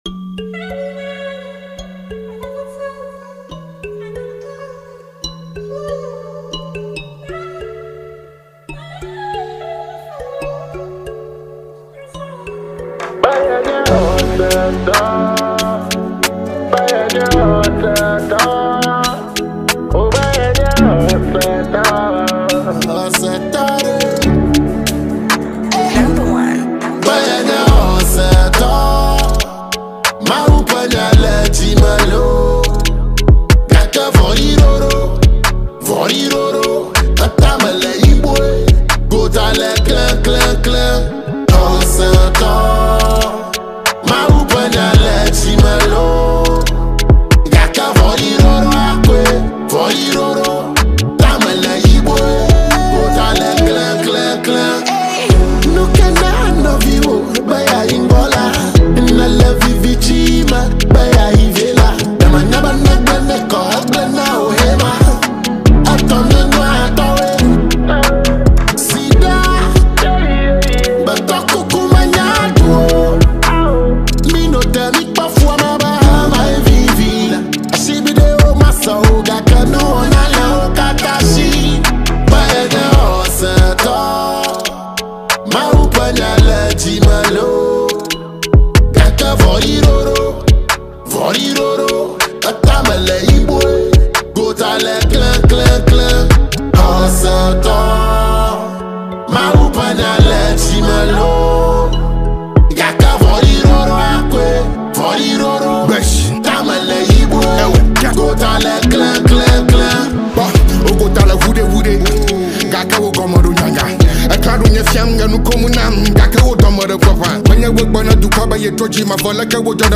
Ghanaian Afro-dancehall